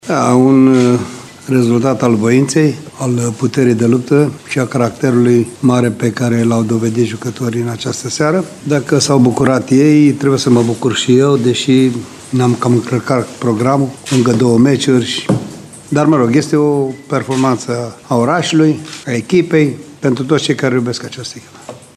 Tehnicianul bănățean și-a felicitat elevii, dar a explicat și cum a gândit schimbările:
29-mar-6.30-Ionut-Popa-o-victorie-a-vointei.mp3